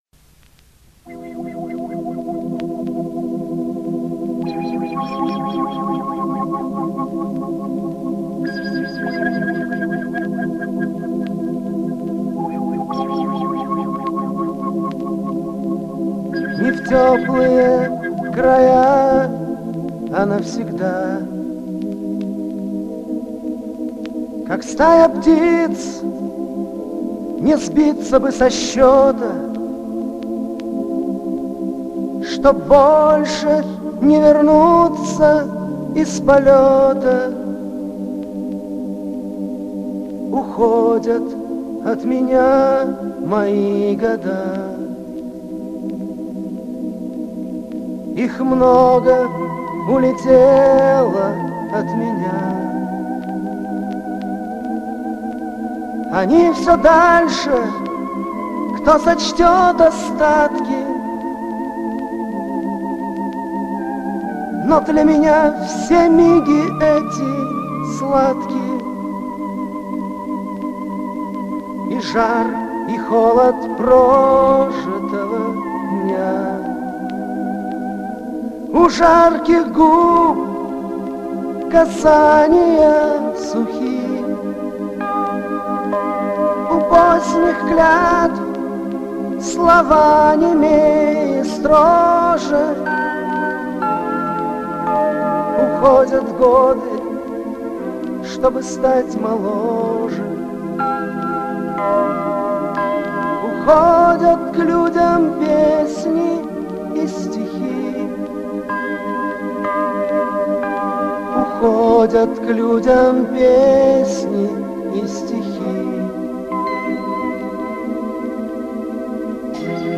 Чуть-чуть бы погромче
ЗДЕСЬ И ТАК АМПЛИТУДА УЖЕ ЗАВЫШЕНА.